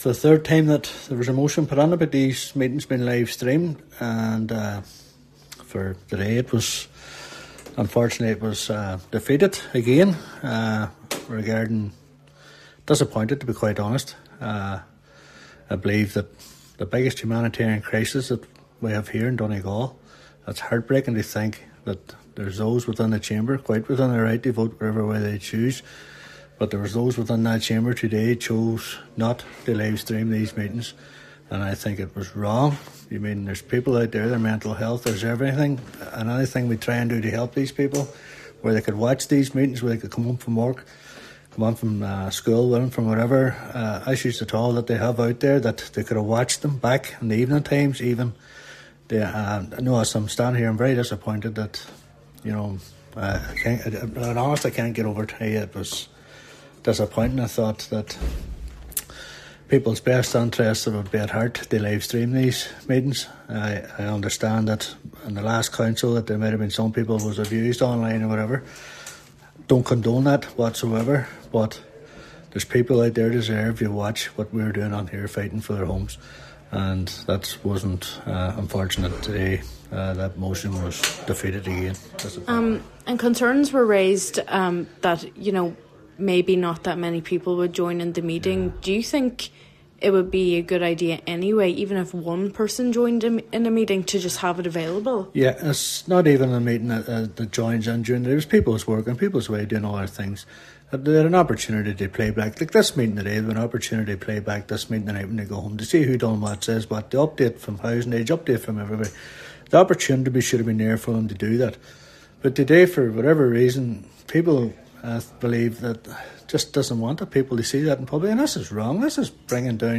Cllr Devine says he’s disappointed…………………..